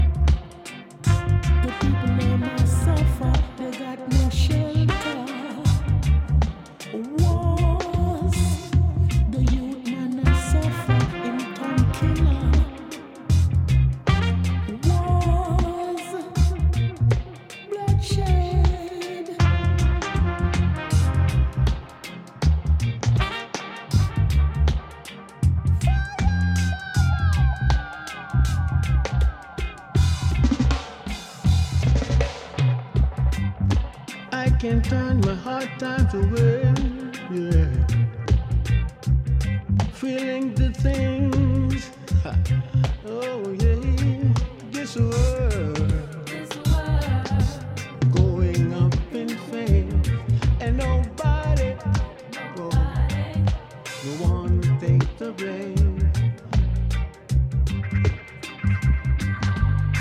Dub Reggae